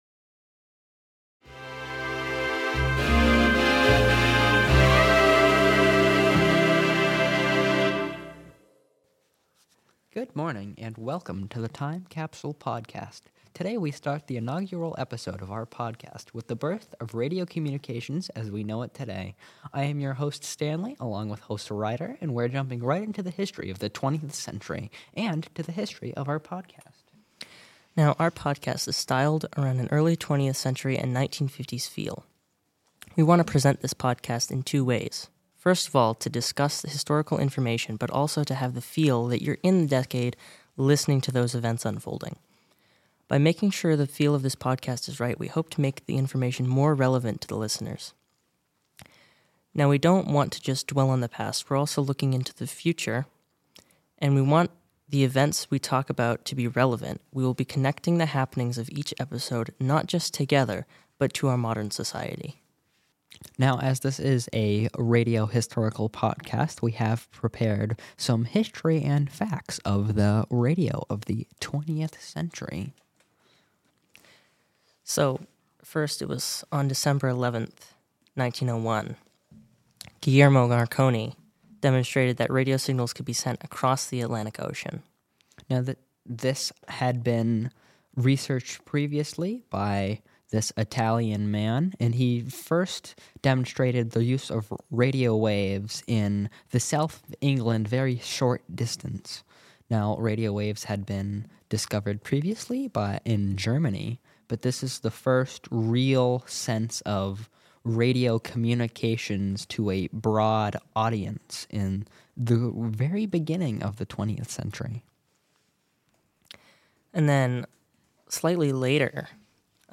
Big Band Brass Solo